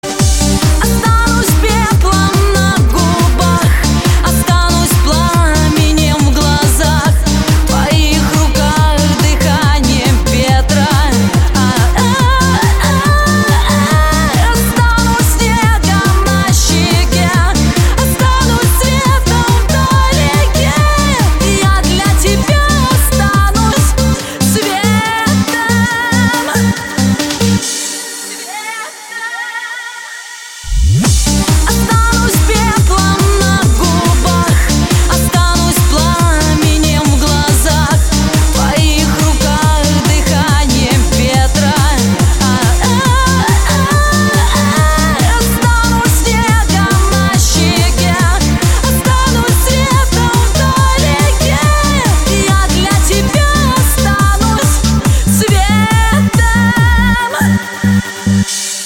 • Качество: 160, Stereo
Ремикс на композицию группы